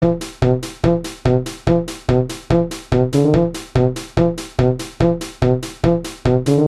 I'w been listening to a lots of Pantera and Metallica lately so I made this.
I can hear Metalica's influence in your music. It's kinda short though.